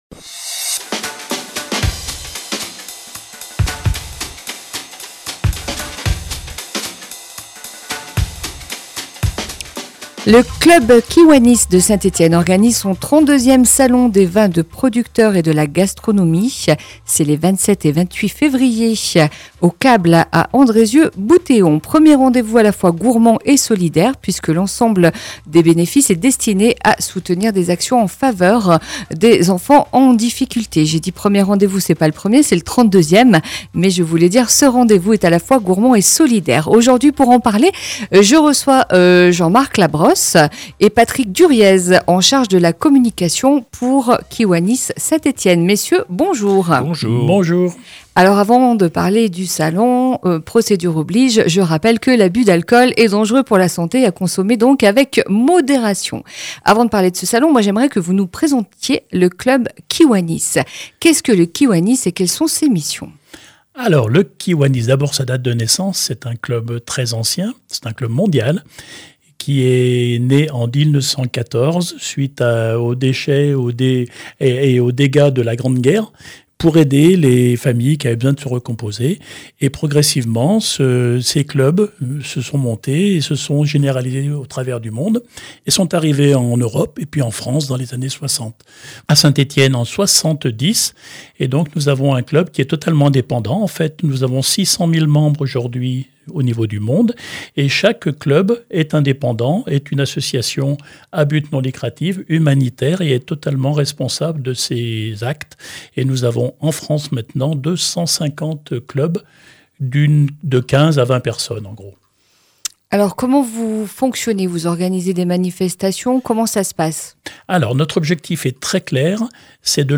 Pour en parler, nous recevons aujourd’hui, à 11h30, sur Radio Ondaine, le Club Kiwanis de Saint-Etienne.